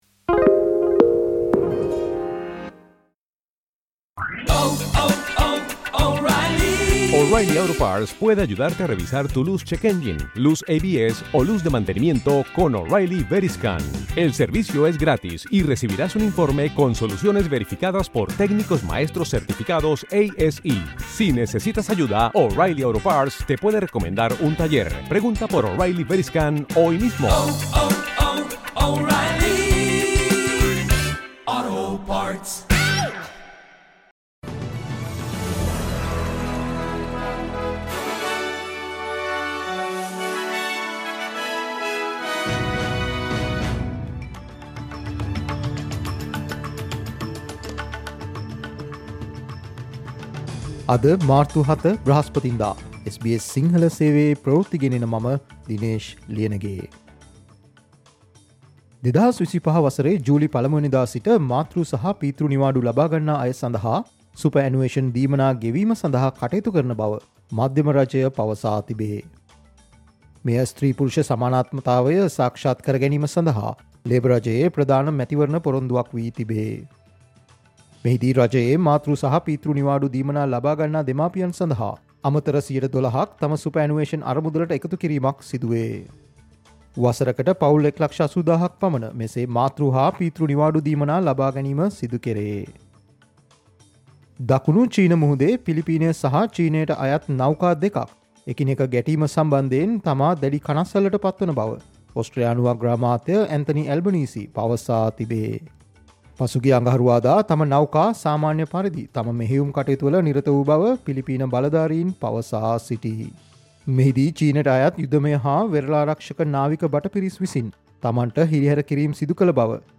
Australia news in Sinhala, foreign and sports news in brief - listen, Thursday 07 March 2024 SBS Sinhala Radio News Flash